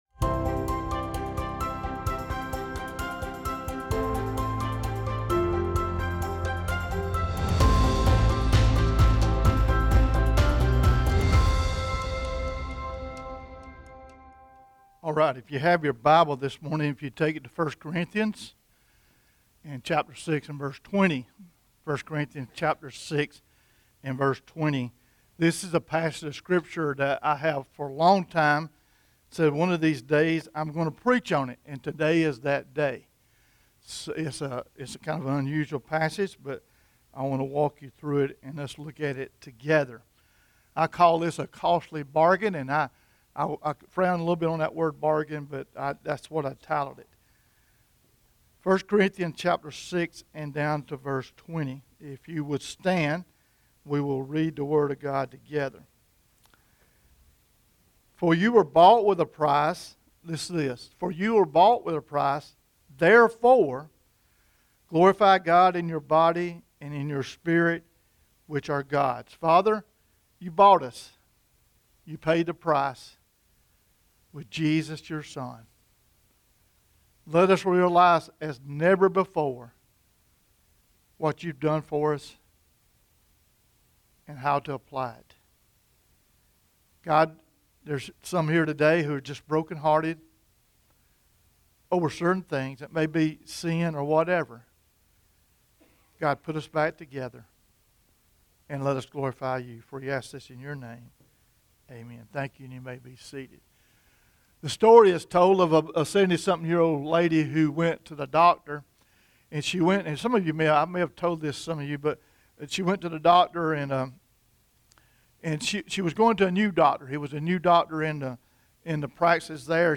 Sermon-2-13-22-audio-from-video.mp3